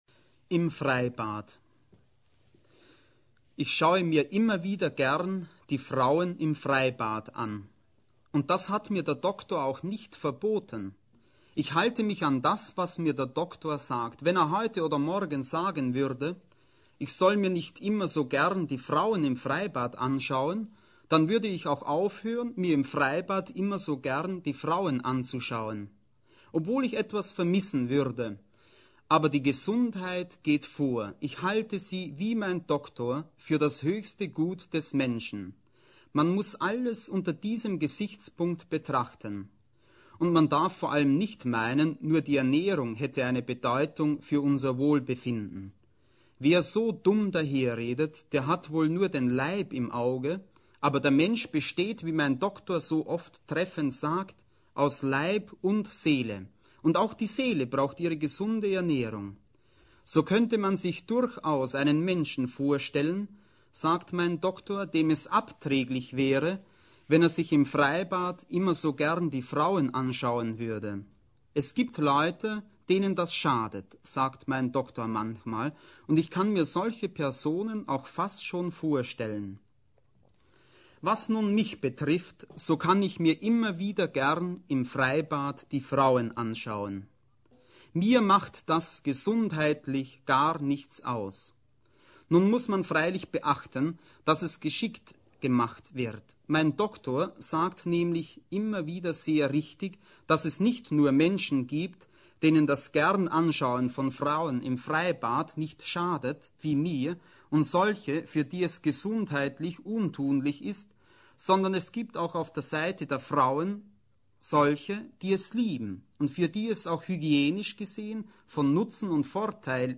Alois Brandsetter liest Kurzprosa.
08-alois-brandstetter-hoerprobe.mp3